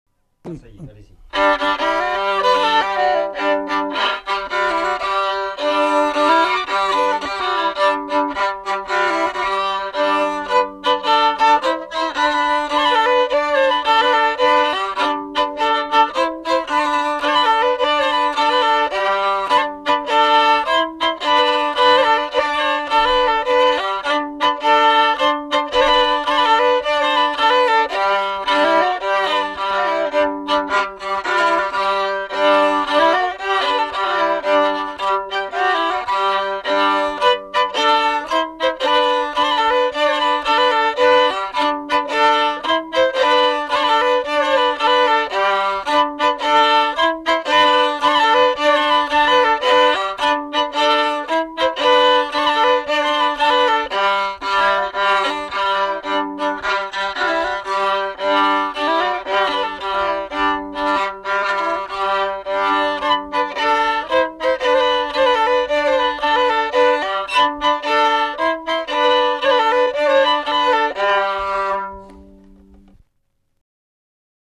Aire culturelle : Haut-Agenais
Lieu : Castillonnès
Genre : morceau instrumental
Instrument de musique : violon
Danse : rondeau